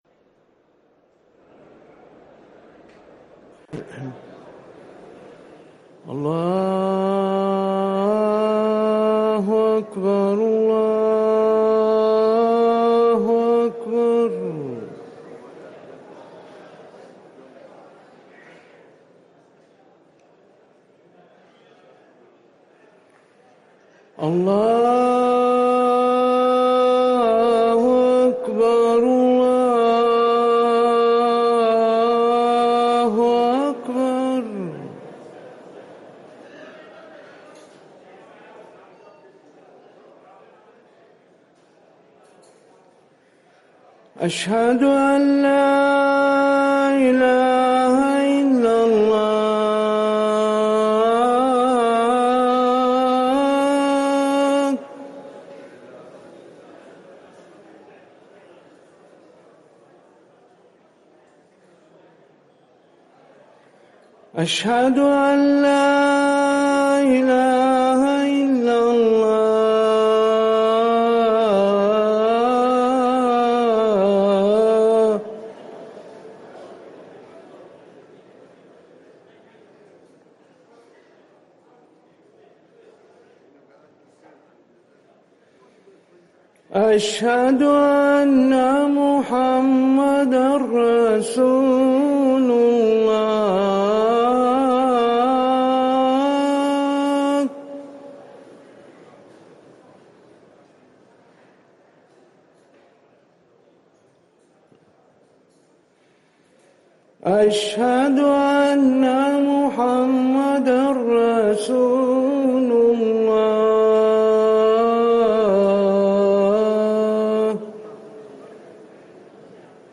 اذان العشاء